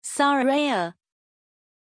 Pronunciation of Sorayah
pronunciation-sorayah-zh.mp3